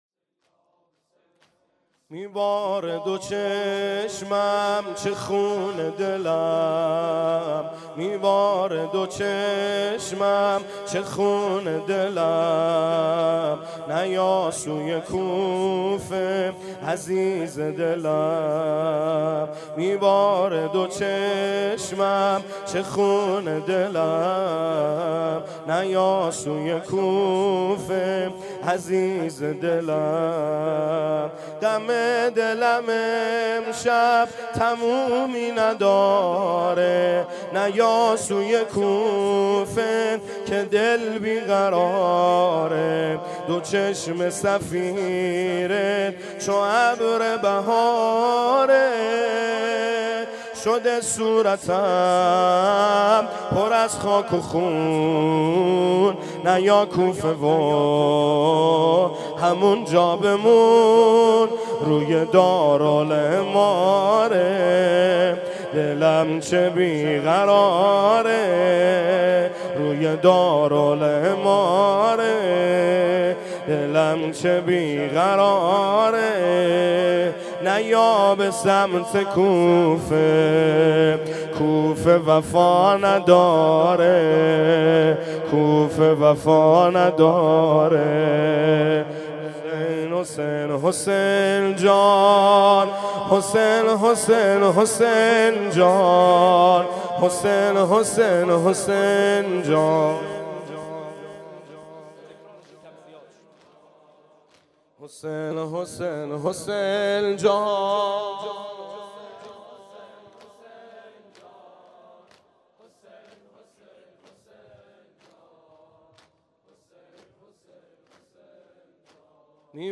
زمینه - شب اول محرم 1396 - دوباره به عشق تو سینه زنم